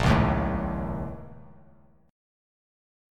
BbmM7bb5 chord